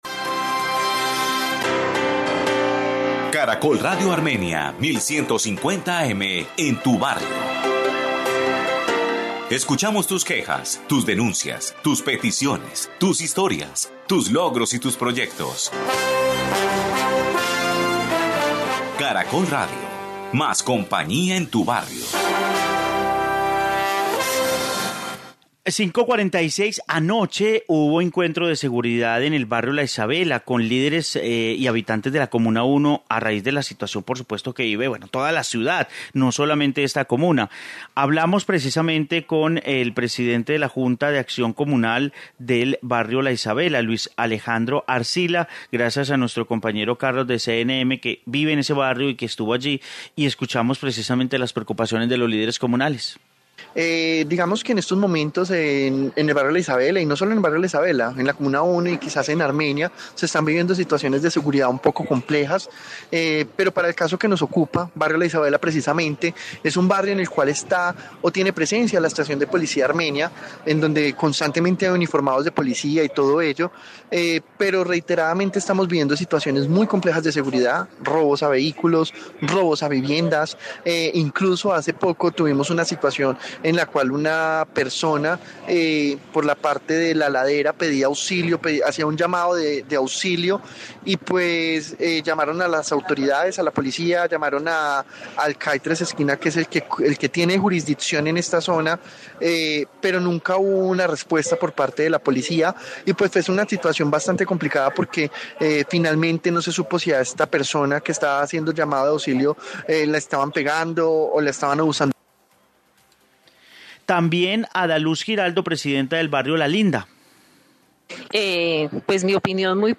Informe seguridad La Isabela en Armenia
Caracol Radio en tu barrio desde La Isabela, la inseguridad preocupa a los habitantes ...